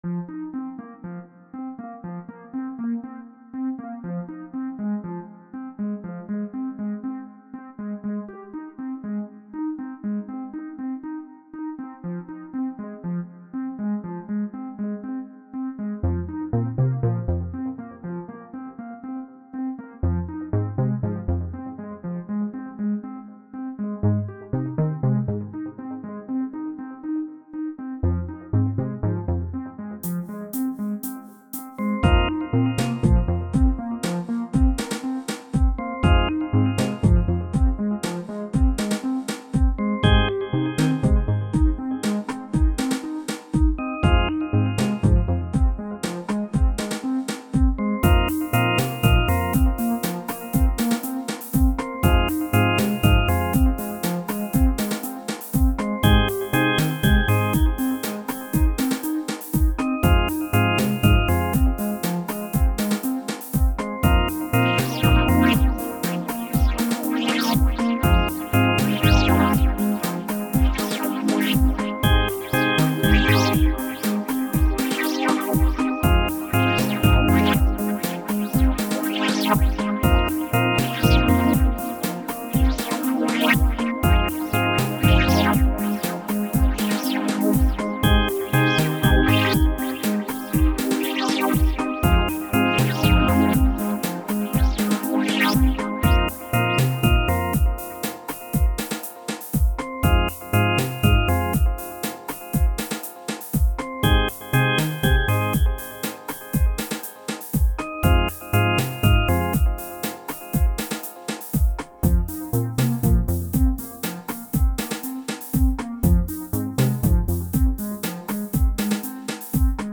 Genre Chillout
This track is the remix